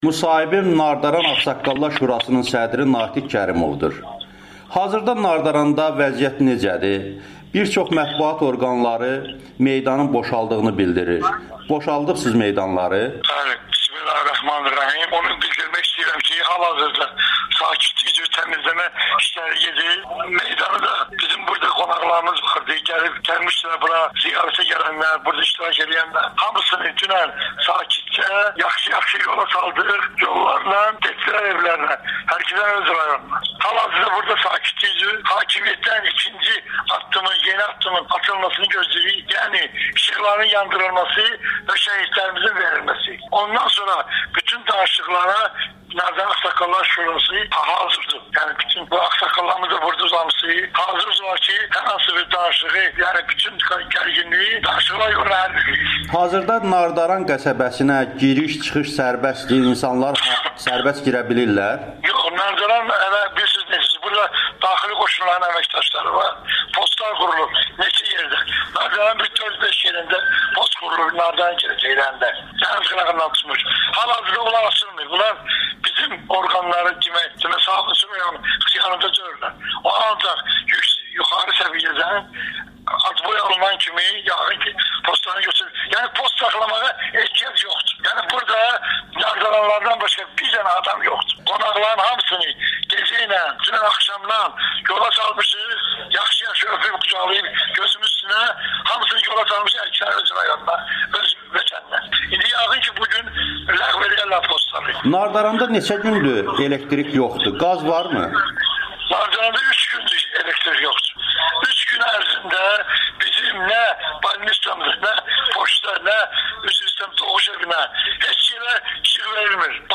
problem danışıqla həll olunmalıdır [Audio-müsahibə]